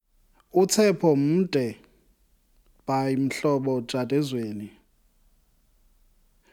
Conheça o ritmo do isiXhosa na voz do seu autor